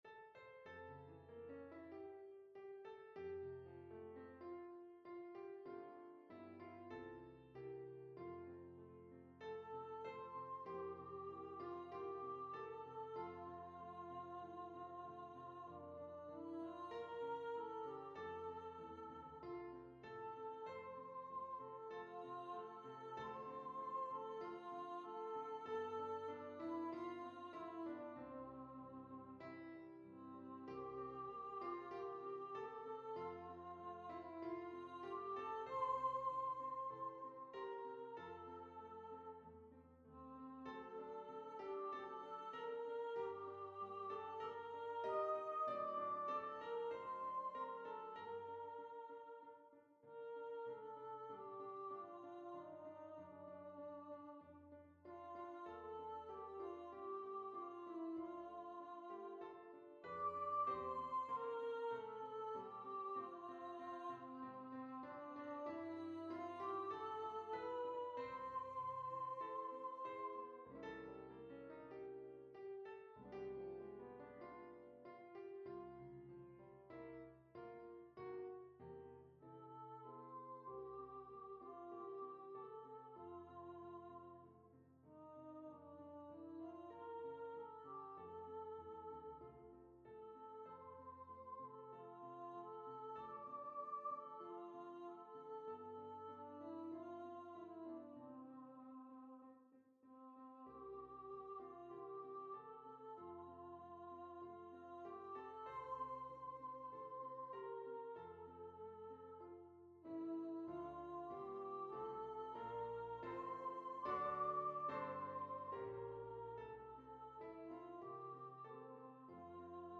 Voices: Solo Voice Instrumentation: Piano
NotePerformer4 mp3 Download/Play Audio